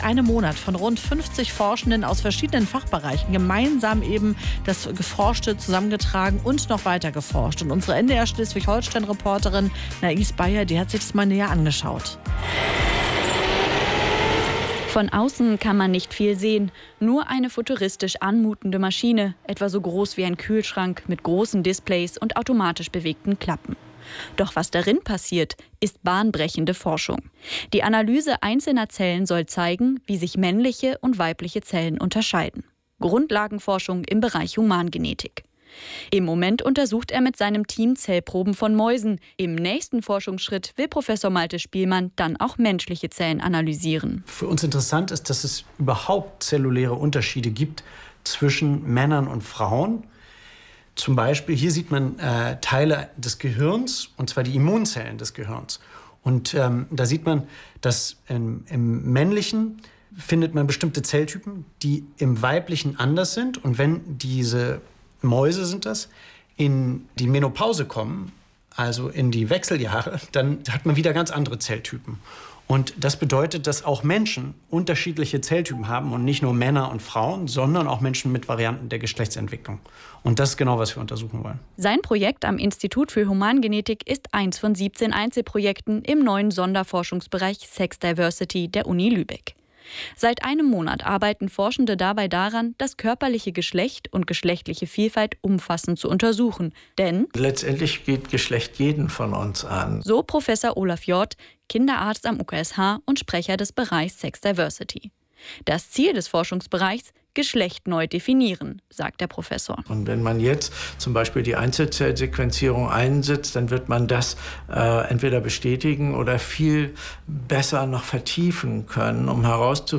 Radiobeitrag NDR 1 Welle Nord vom 10. Juli 2024